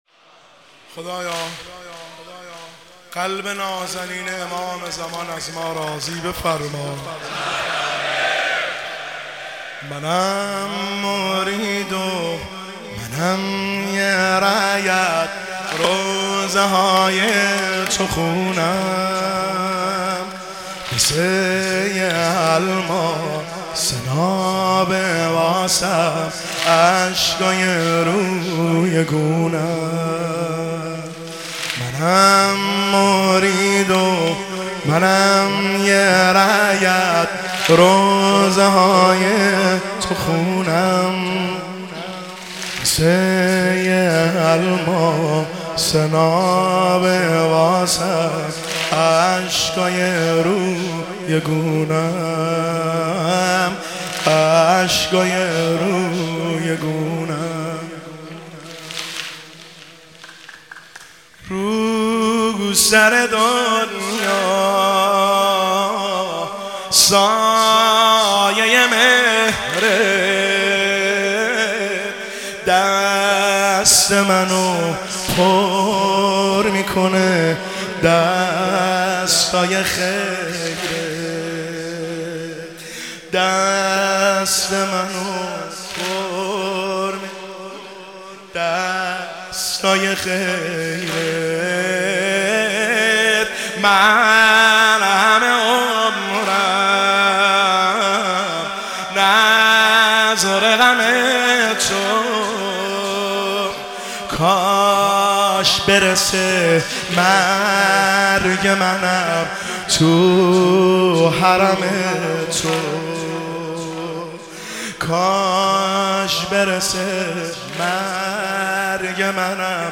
منم مرید و منم یه رعیت سنگین – شب دوم محرم الحرام 1404 هیئت خادم الرضا قم
سنگین – شب دوم محرم الحرام 1404